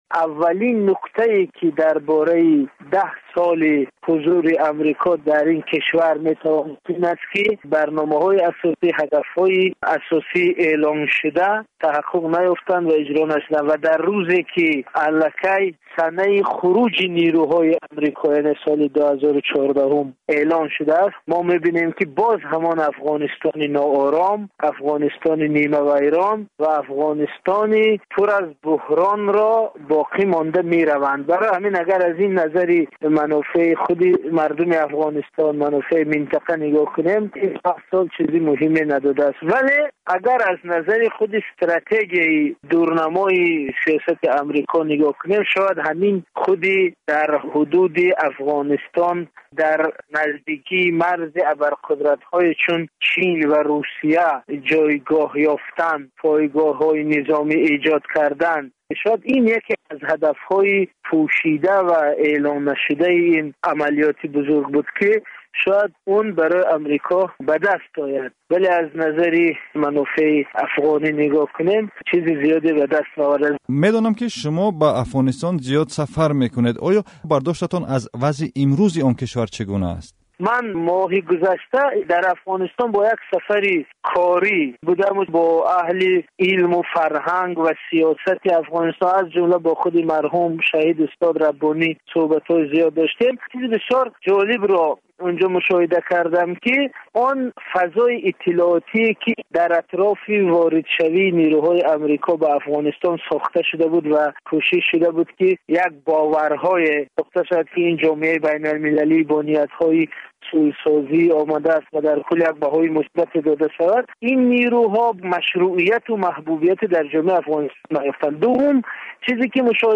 Сӯҳбати